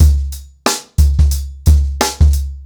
TheStakeHouse-90BPM.13.wav